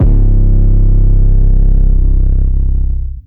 808 SLIDES.wav